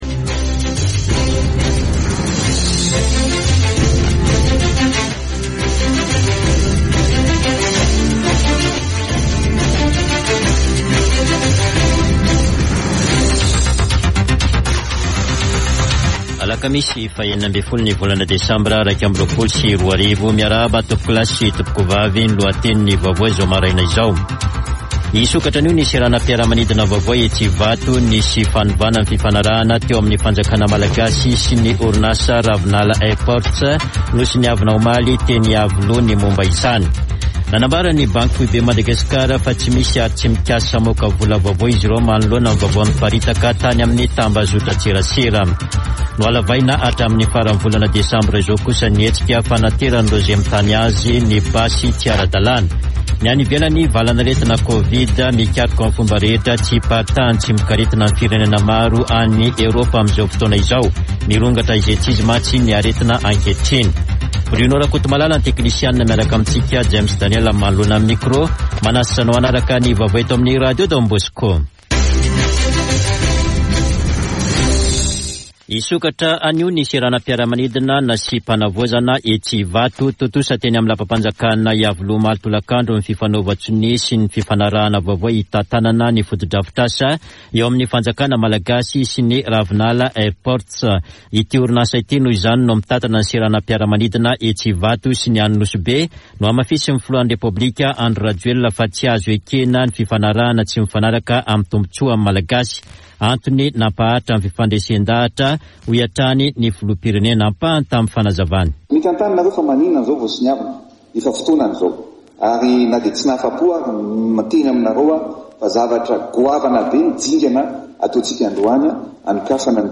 [Vaovao maraina] Alakamisy 16 desambra 2021